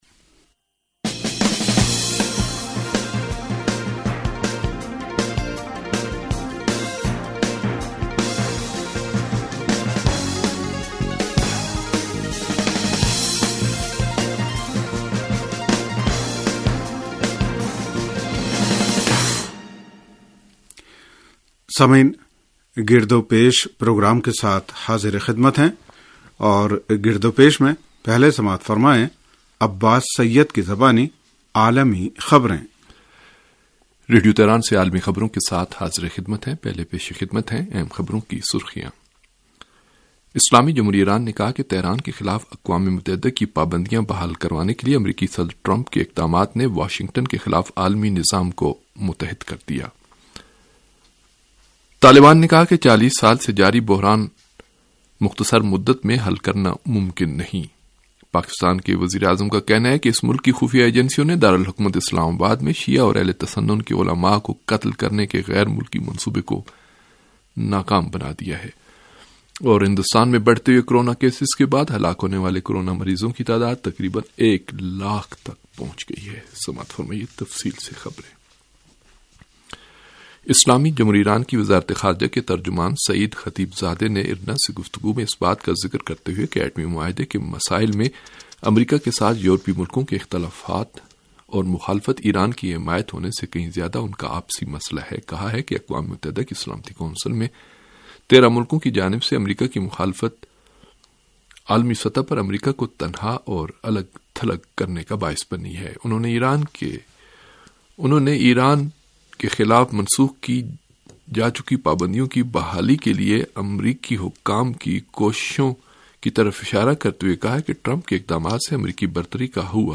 ریڈیو تہران کا سیاسی پروگرام - گرد و پیش